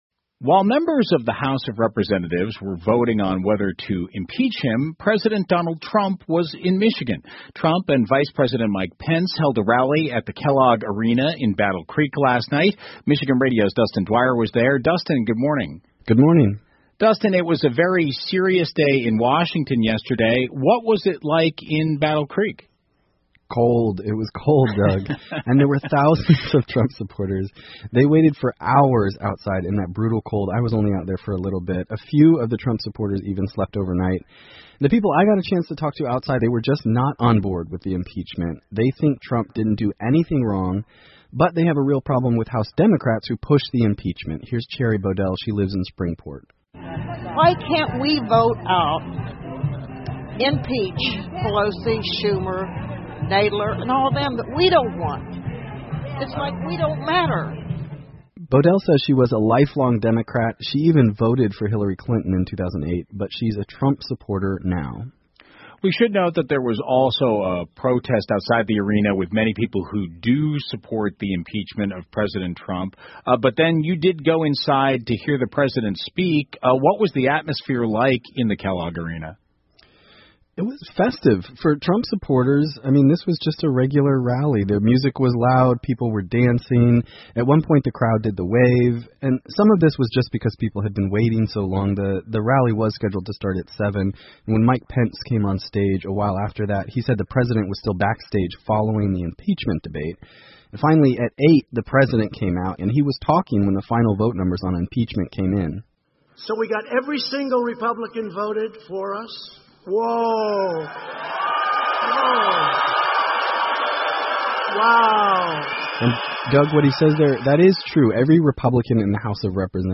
密歇根新闻广播 特朗普来到密西根拉票 听力文件下载—在线英语听力室